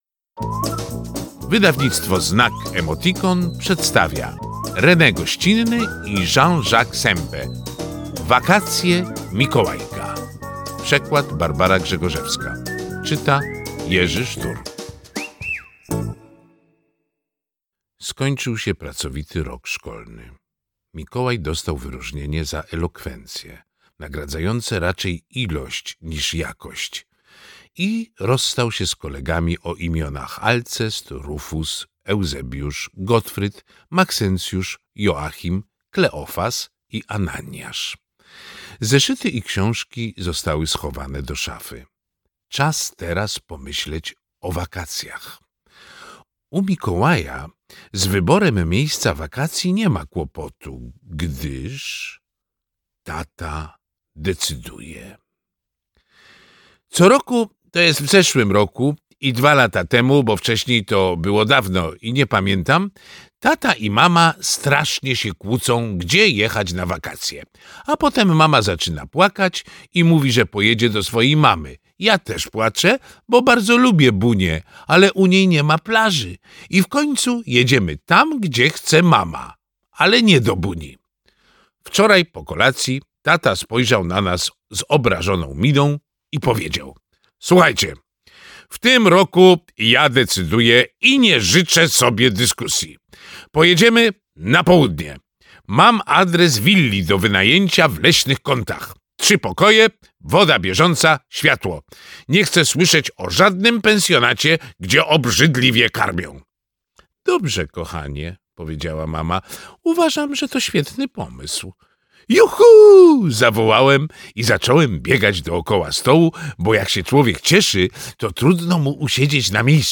Wakacje Mikołajka - Rene Goscinny, Jean-Jacques Sempe - audiobook